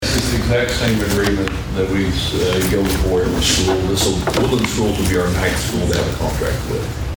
The Board of Osage County Commissioners convened for a regularly scheduled meeting at the fairgrounds on Monday.
Sheriff Bart Perrier details the contract.